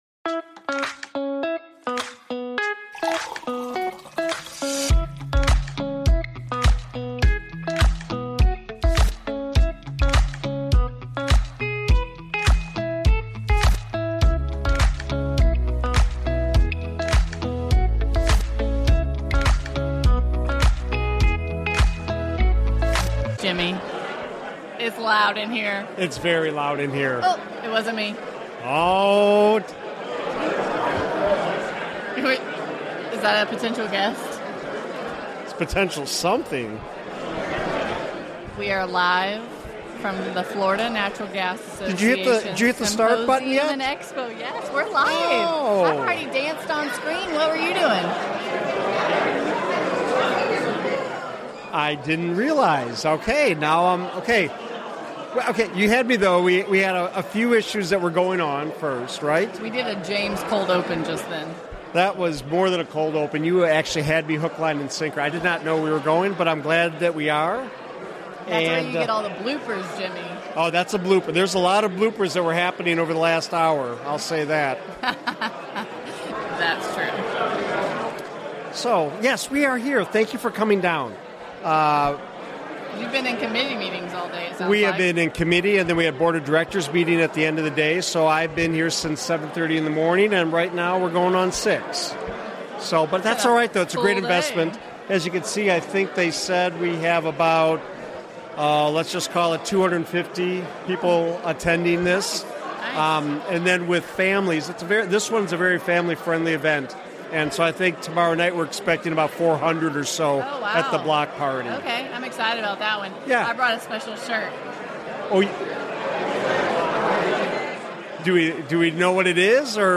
Live @ Florida Natural Gas Association Symposium + Expo